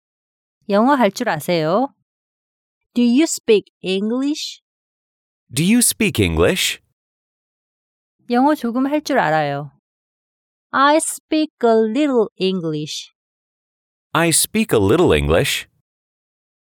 ㅣ두유 스삐익 이잉글리쉬ㅣ
ㅣ아이 스삑 어리를 이잉글리쉬ㅣ